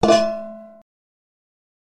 Frying Pan Hit
The sound of a frying pan or skillet hitting someone in the head, great for cartoon or movie scene.